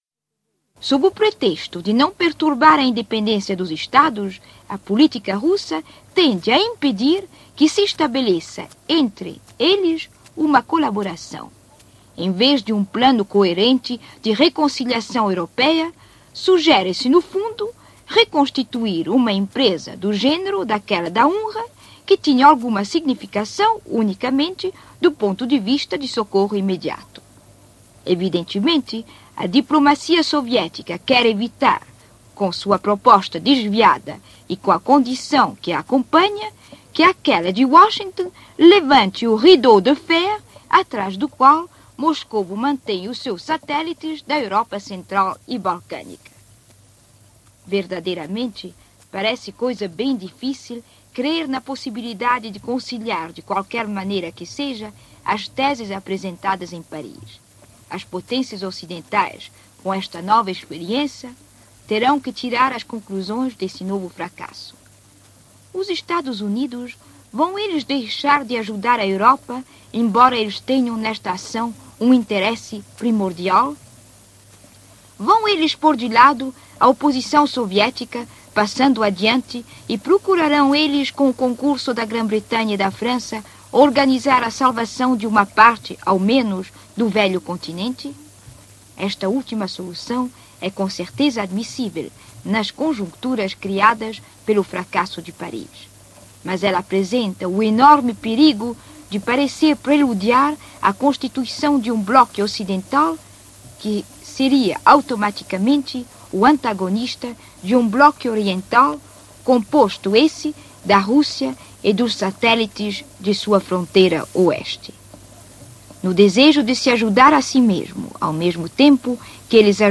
Extrato de um programa de rádio da Rádio Suíça Internacional de 3 de julho de 1947